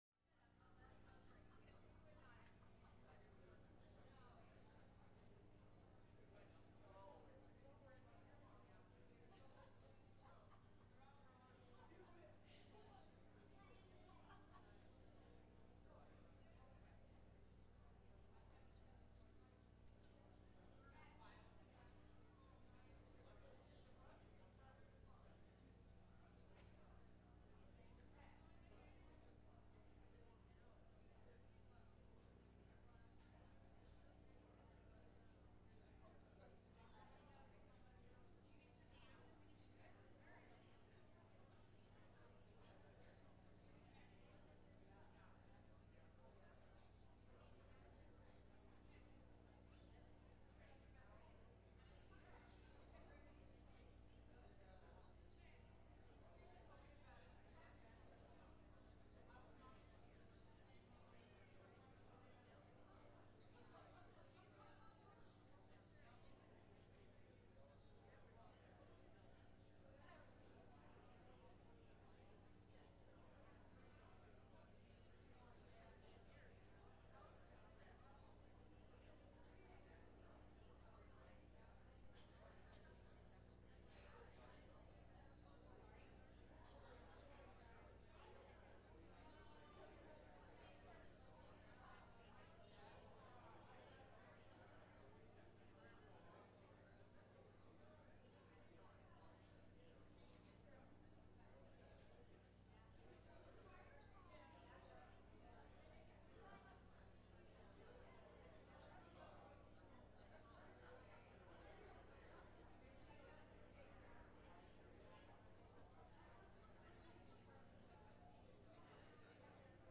7_18-21-sermon.mp3